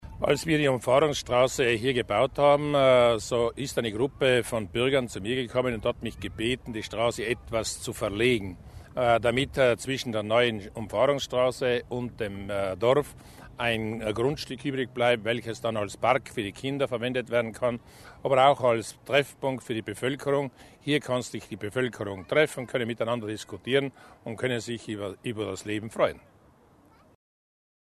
Landesrat Mussner über den neuen Park